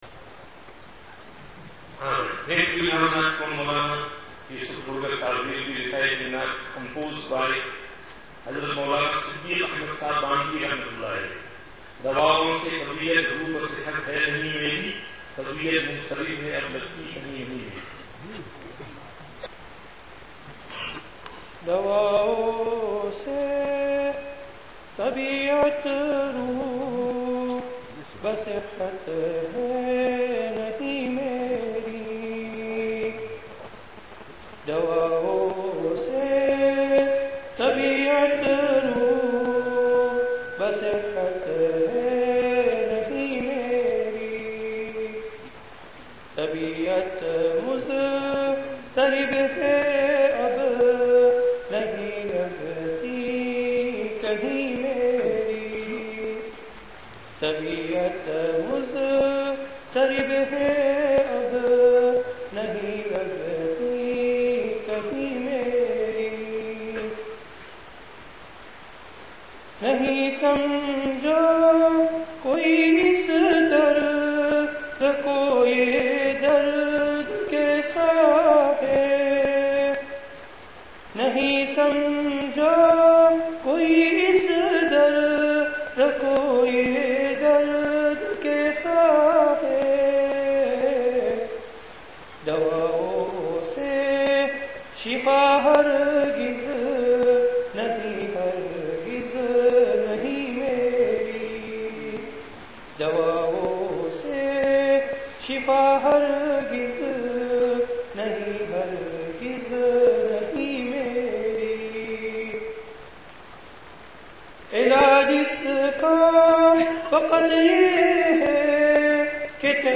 Bukhari Graduation 2008 Darul-Uloom, Madinatul-Uloom
Naat & Nasheeds